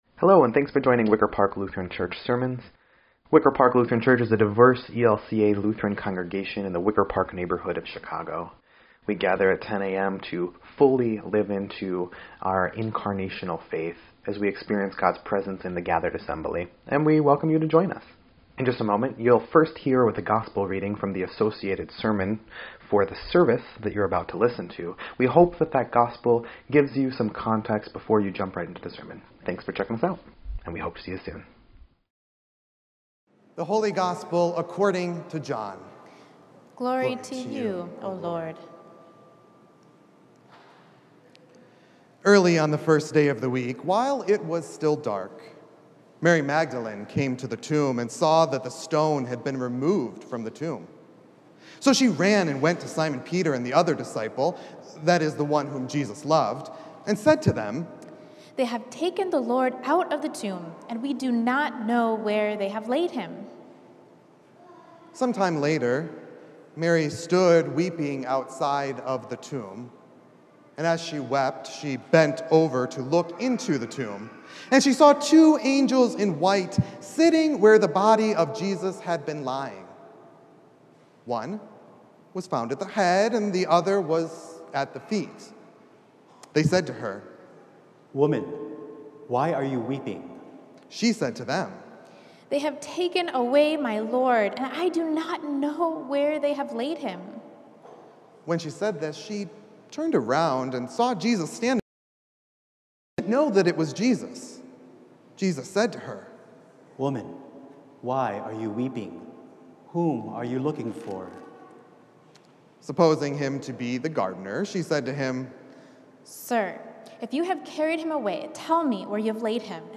7.25.21-Sermon_EDIT.mp3